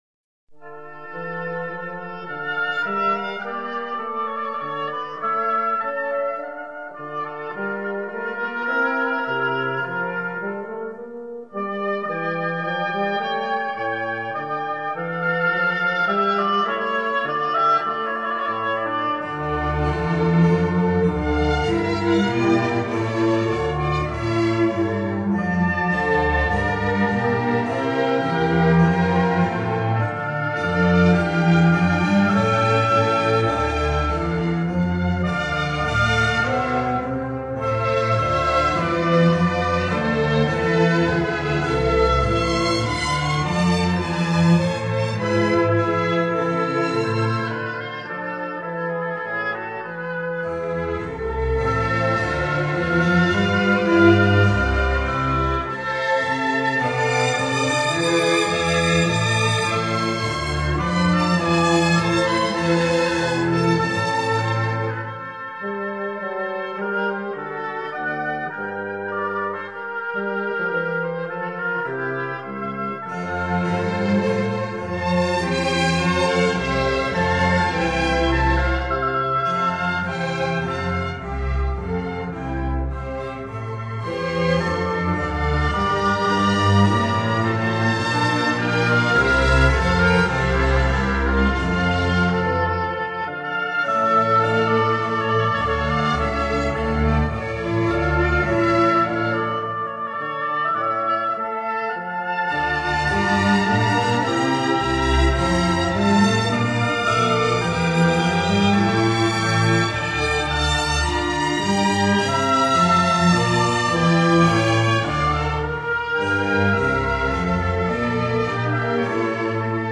3）这是行板到快板的舞曲。
这里说行板到快板，就是指音乐的演奏速度由行进式逐步变为快速。
是一部管弦乐组曲
乐器使用了小提琴、低音提琴、日耳曼横笛、法兰西横笛、双簧管、圆号、小号等